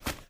STEPS Dirt, Run 25.wav